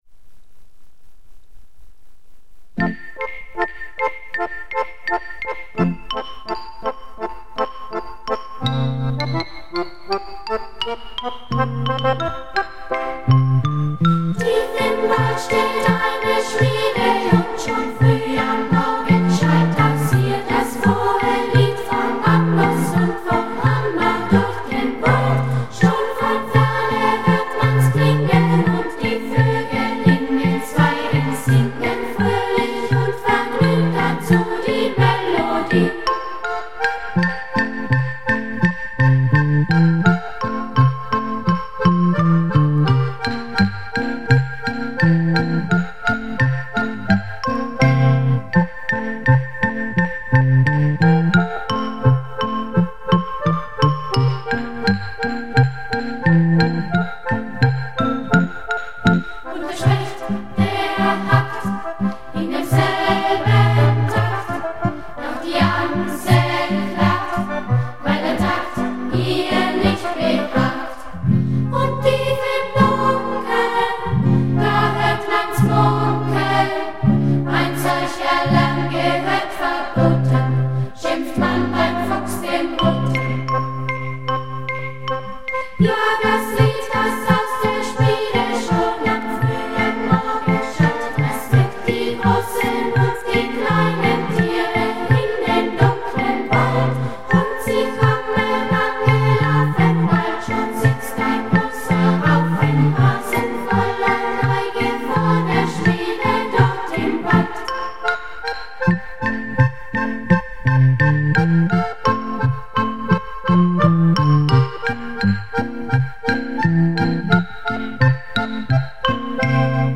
bestand aus mehreren Gitarren
Akkordeon
und dem Schlagzeug